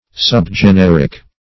Subgeneric \Sub`ge*ner"ic\